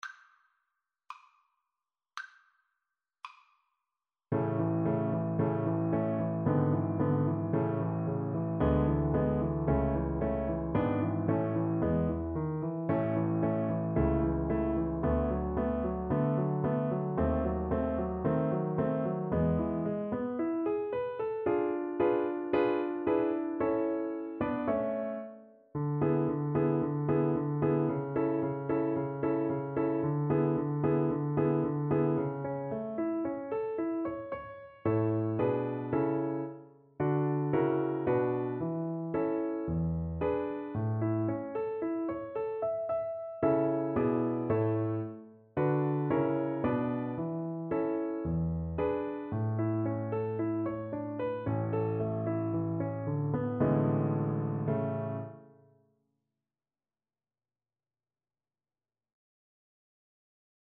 ~ = 56 Affettuoso
Classical (View more Classical Cello Music)